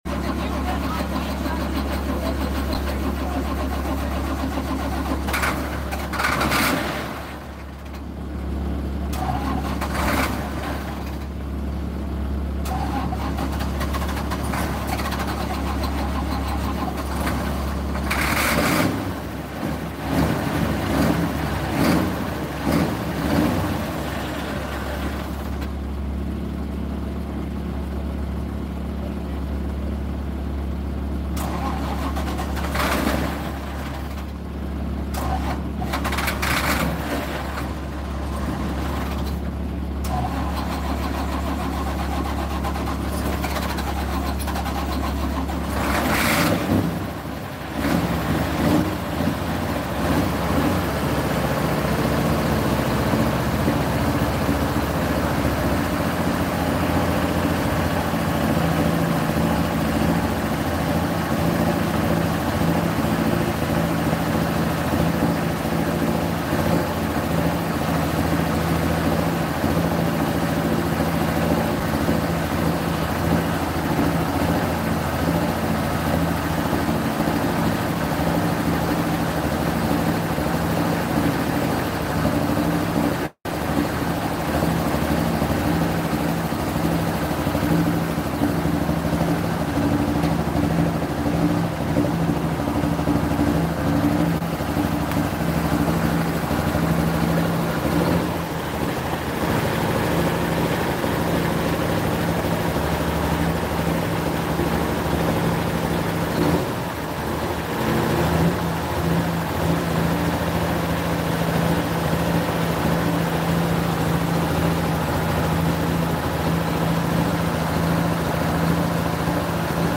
Firing up the angry "Wild Buck" John Deere